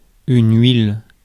Ääntäminen
IPA: [ɥil]